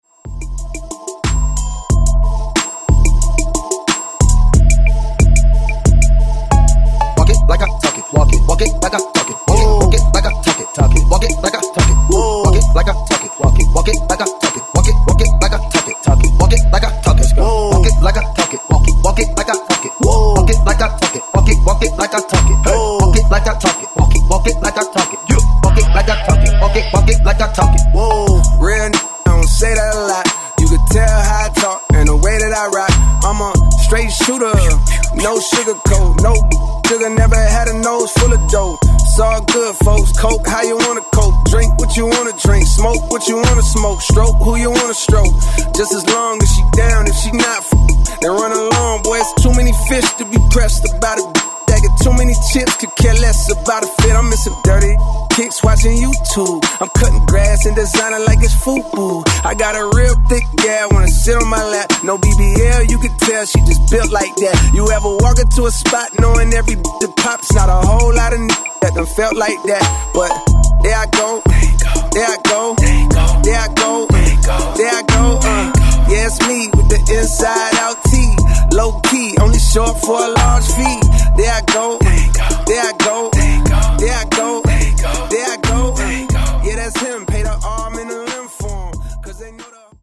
Genres: 80's , RE-DRUM
Clean BPM: 121 Time